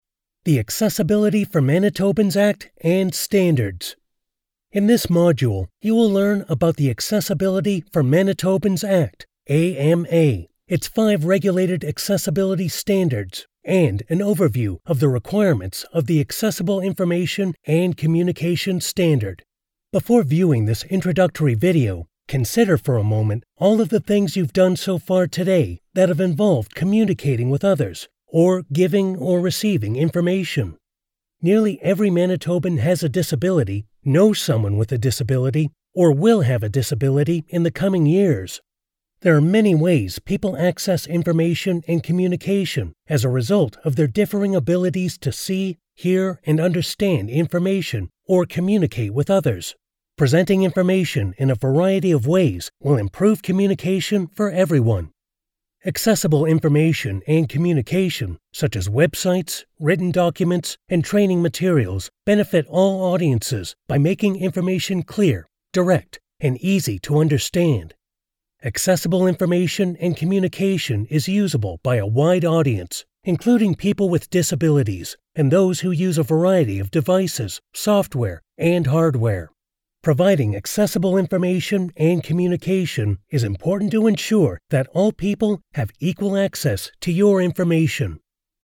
Listen Back Play Pause Stop Forward 00:00 00:36 Mute Unmute Audio narration.